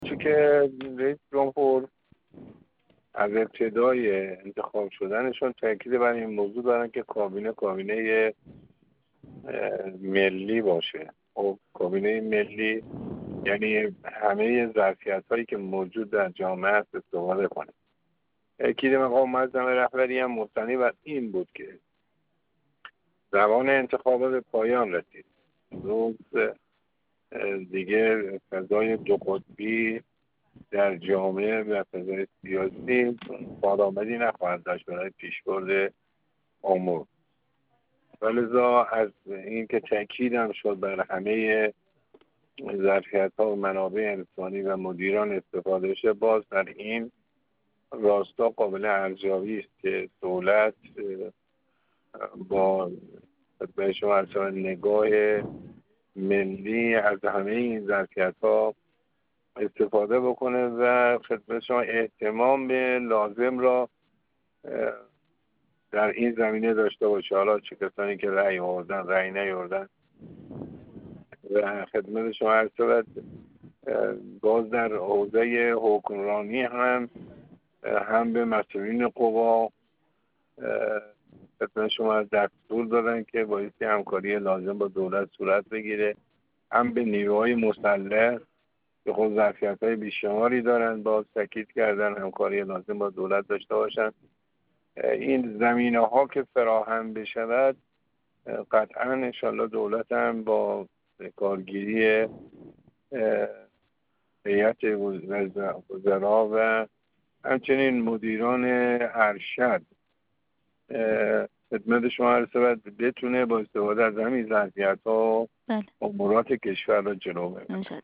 محمدصالح جوکار، نماینده مردم یزد در دوازدهمین مجلس شورای اسلامی
گفت‌وگو